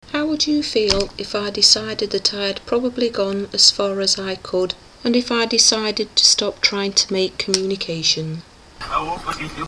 Sony B300 and reversed Russian background